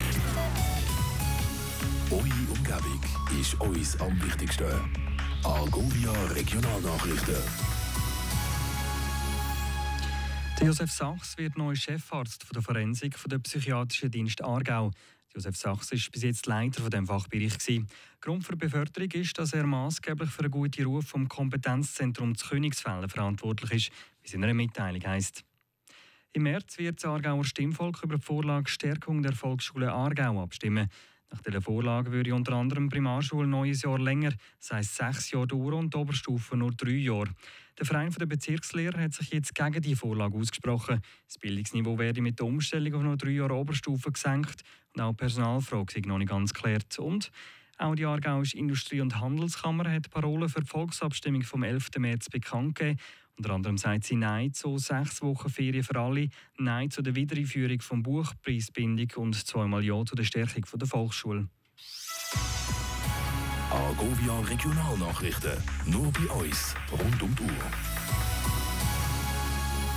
Januar - Radio Argovia Regionalnachrichten